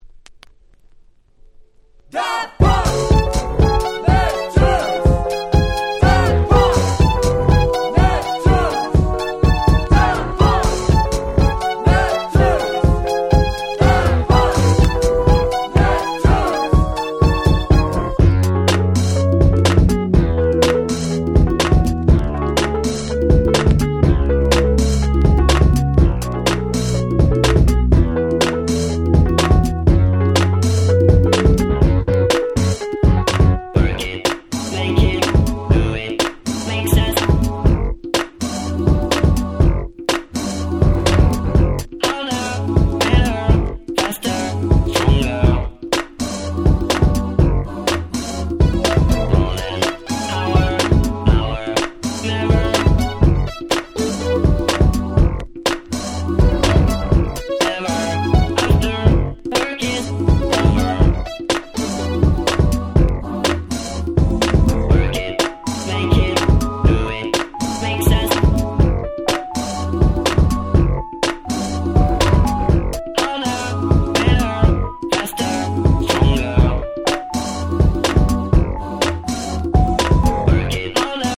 01' 世界的大ヒットDisco !!!